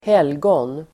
Uttal: [²h'el:gån]